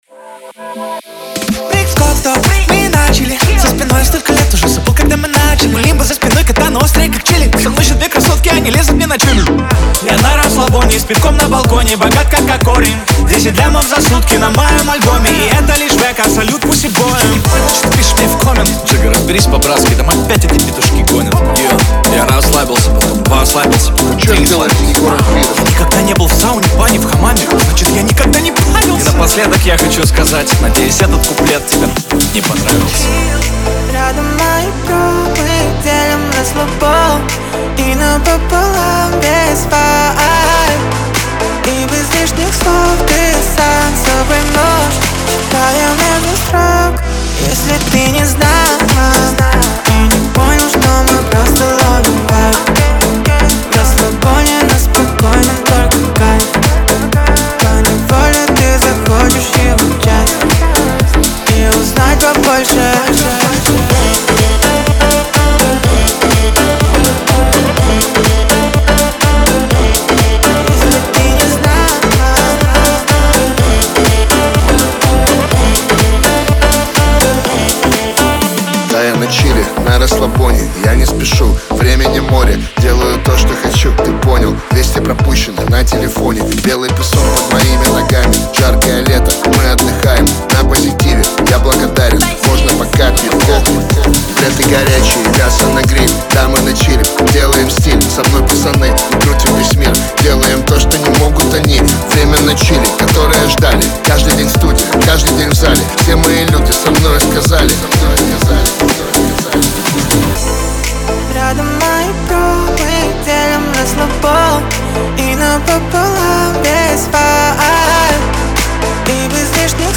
это зажигательная композиция в жанре хип-хоп и R&B